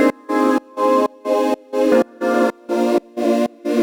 Index of /musicradar/sidechained-samples/125bpm
GnS_Pad-MiscB1:4_125-C.wav